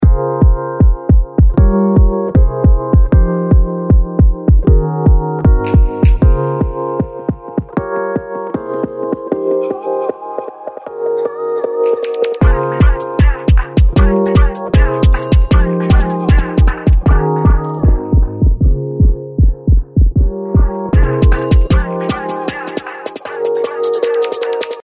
プリセット「Devista Delay」は、COMBO FILTERとDELAYが連動しており、DJミックスの際次の曲へスムースにつなげる時などに有効な、利便性の高いプリセットです。